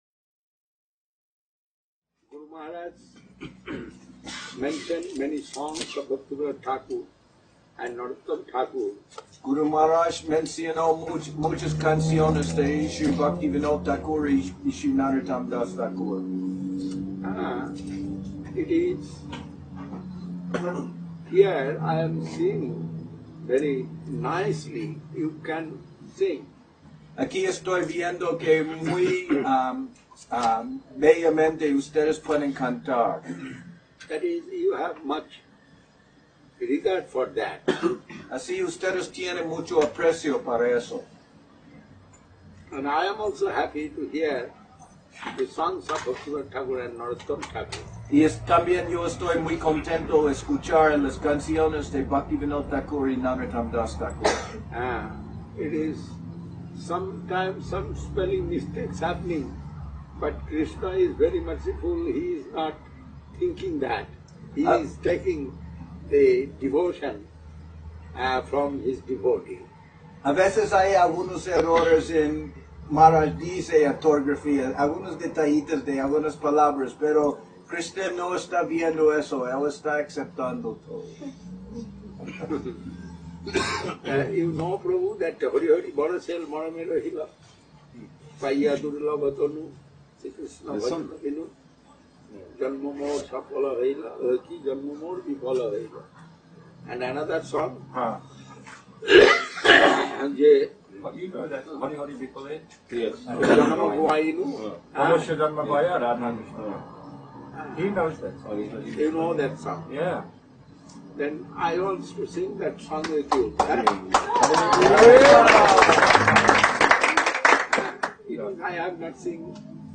Heartfelt prayer